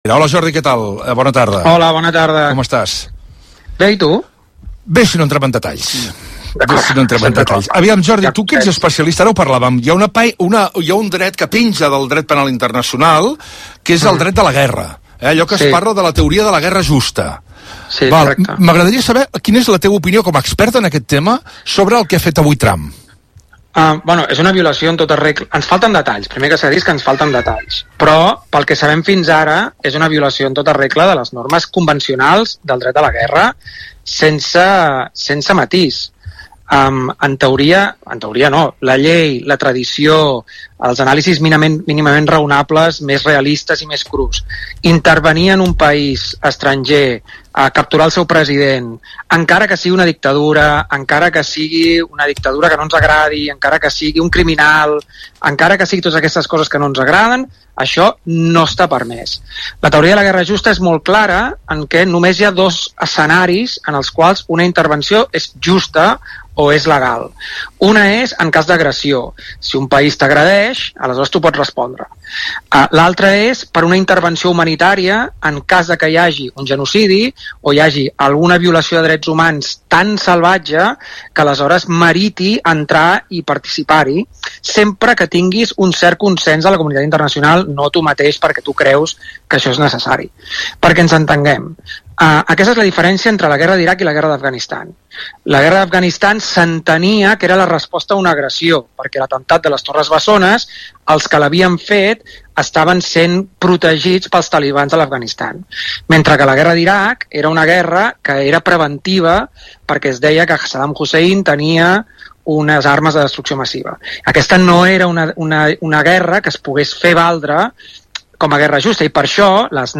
(Aquesta és la transcripció de la intervenció que he fet a l’especial informatiu de RAC1 sobre la intervenció dels Estats Units a Veneçuela amb la captura de Nicolás Maduro.)